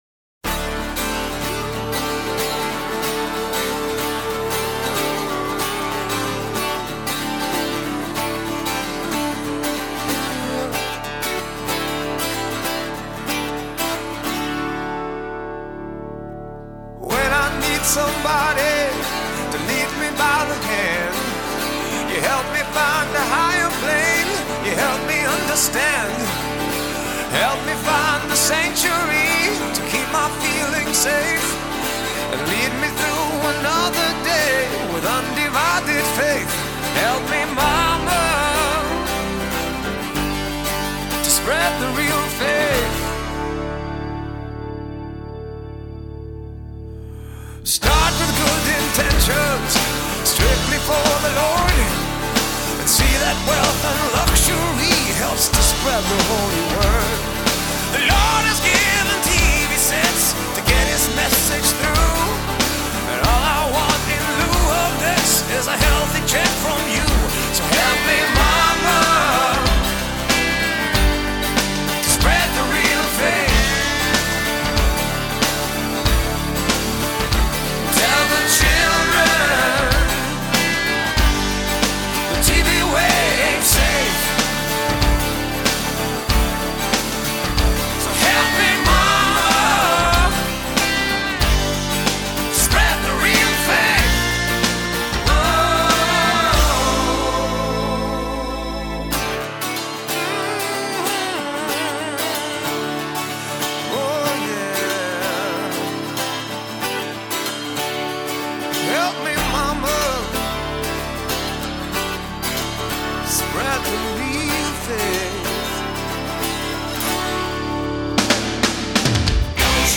A eso le digo yo sonar a Whitesnake, o me equivoco???